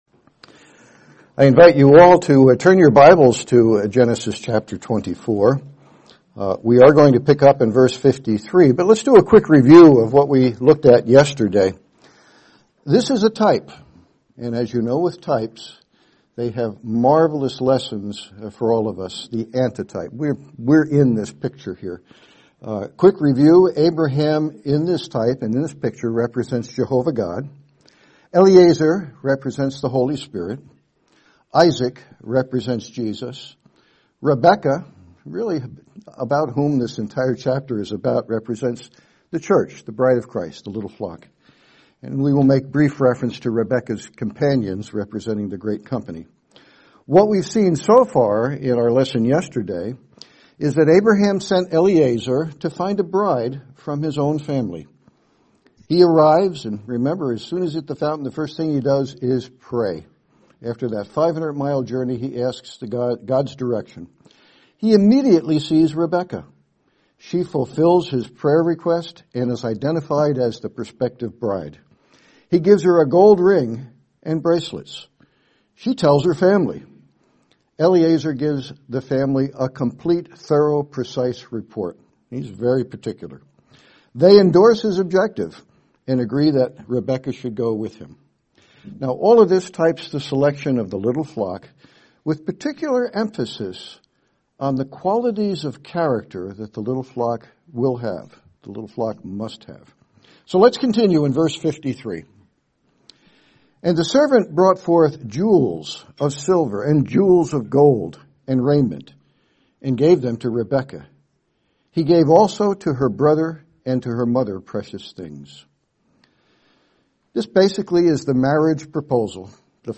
Series: 2025 Wilmington Convention
Service Type: Sermons